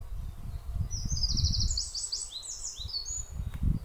Zaļais ķauķītis, Phylloscopus trochiloides
Ziņotāja saglabāts vietas nosaukumsKolka
Zaļais ķauķītis perfekti imitē paceplīti, putns novērots vizuāli dziedam abās dziesmās